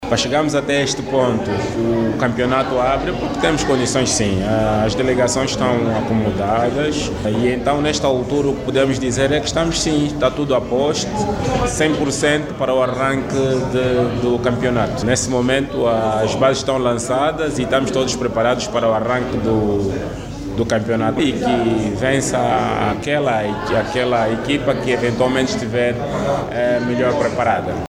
A informação foi confirmada por Gomes Costa, diretor do Gabinete da Juventude e Desportos do Uíge.